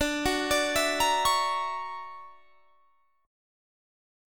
Listen to D+M9 strummed